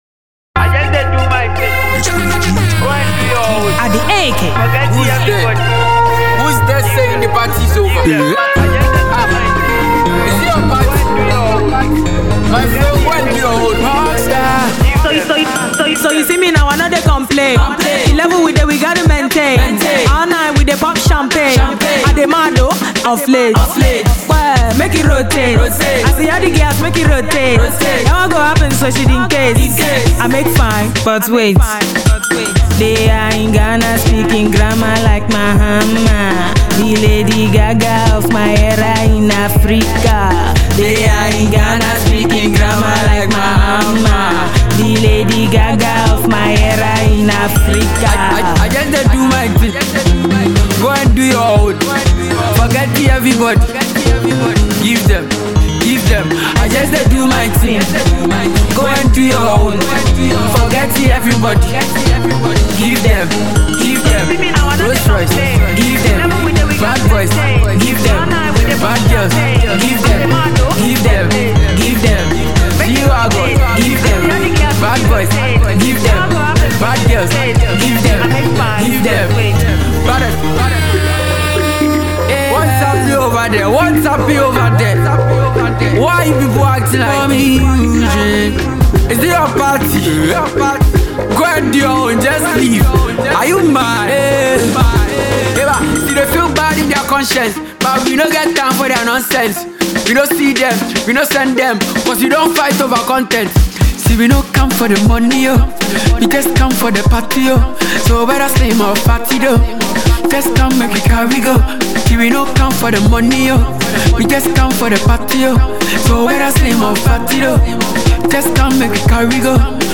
hot new jam